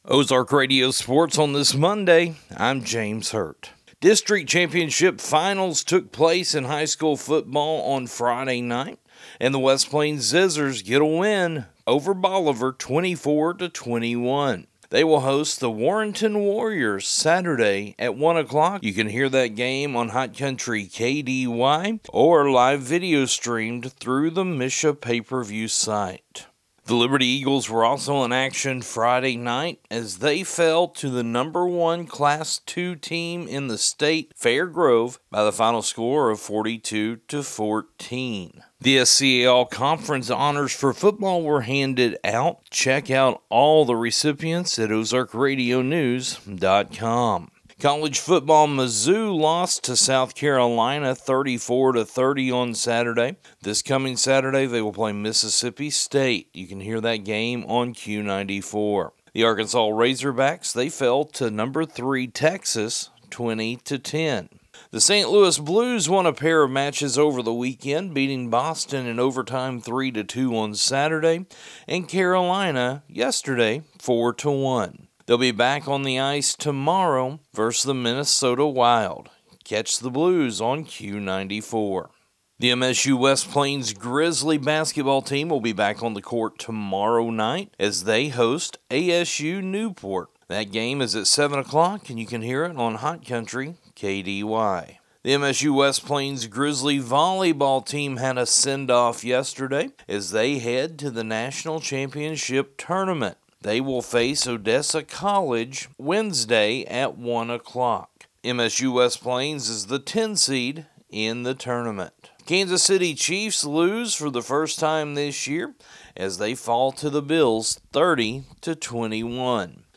Monday Sports Report